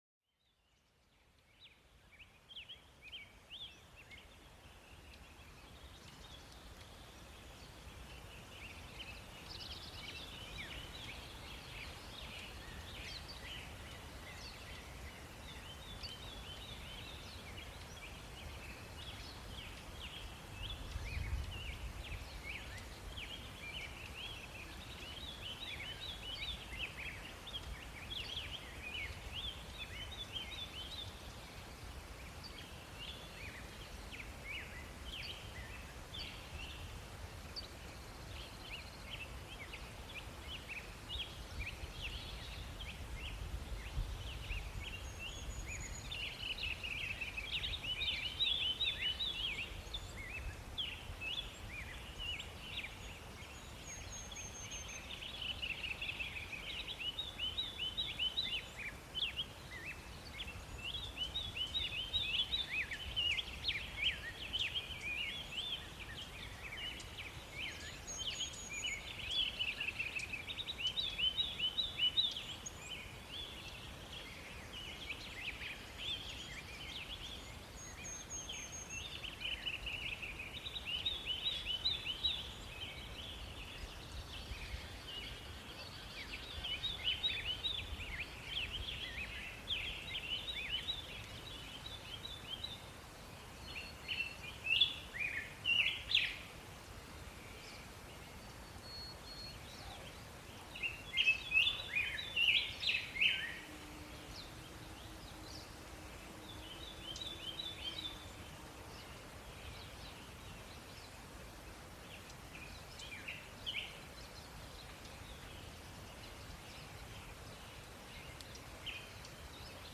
Cet épisode vous emmène au cœur de la campagne, où les oiseaux chantent et le vent caresse les champs. Vous entendrez des bruits naturels authentiques, apaisants et régénérants.
Les sons sont soigneusement sélectionnés pour créer une ambiance propice à la détente.